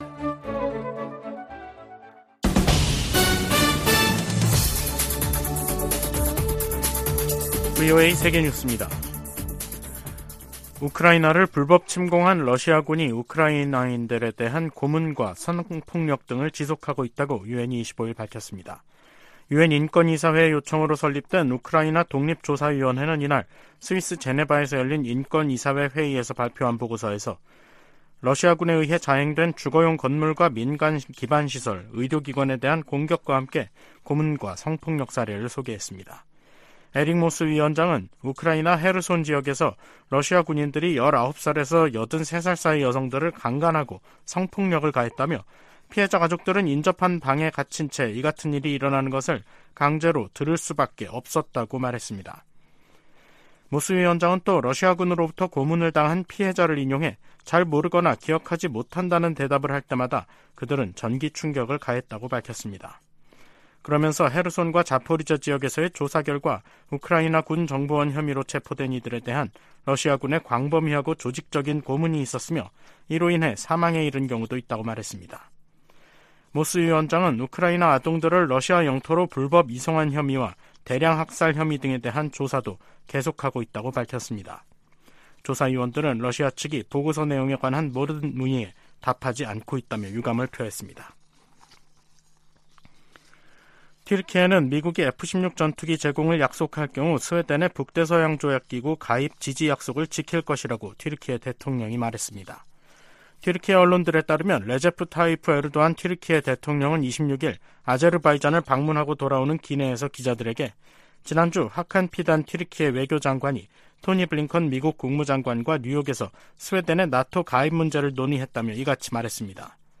VOA 한국어 간판 뉴스 프로그램 '뉴스 투데이', 2023년 9월 26일 3부 방송입니다. 토니 블링컨 미 국무장관은 미한 동맹이 안보 동맹에서 필수 글로벌 파트너십으로 성장했다고 평가했습니다. 한국과 중국, 일본의 외교당국은 3국 정상회의를 빠른 시기에 개최하기로 의견을 모았습니다.